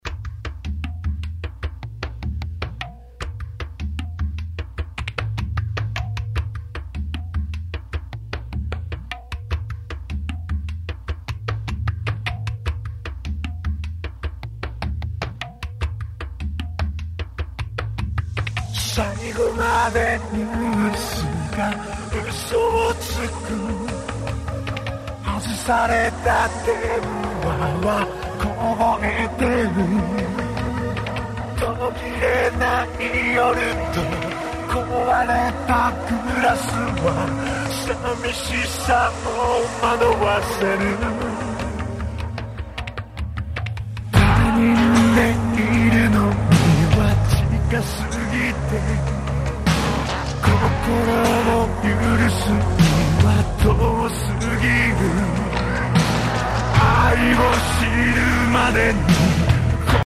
結果：モーターは問題なく回って音楽っぽくはなったけど，ワウフラがまだ気になる → 失敗